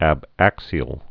(ăb-ăksē-əl)